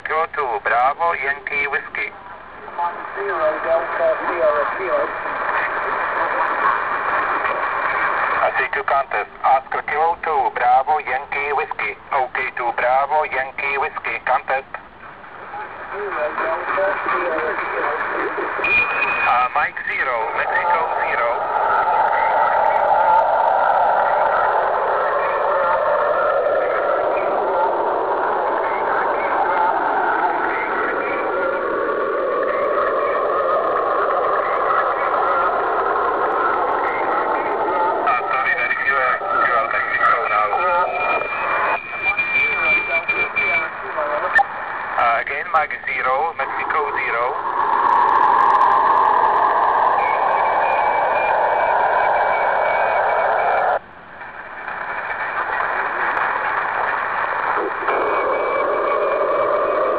Pokud však zjistí, že mají konkurenci, pak se stále více začínají objevovat praktiky "rušení na zakázku". Ale poslechněte si jak rušení na zakázku se projevuje v praxi. (Směr byl identifikován z Balkánu).